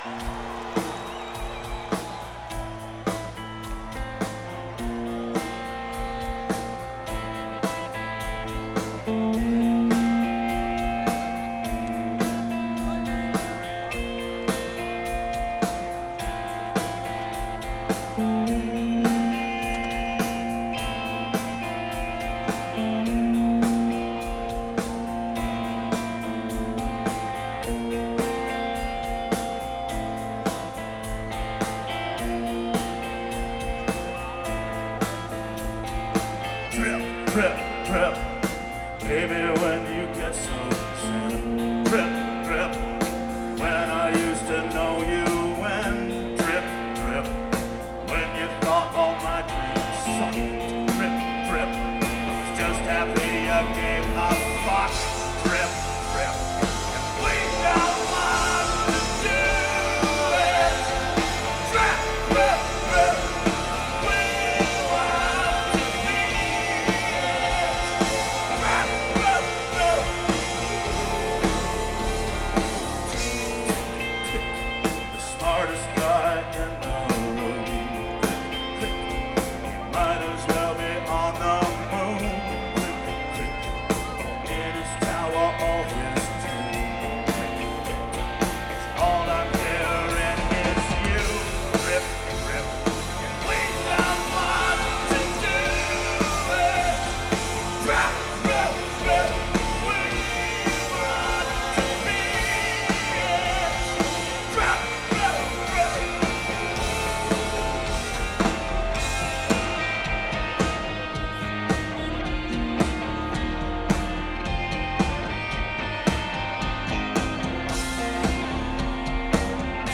(12th Live Performance)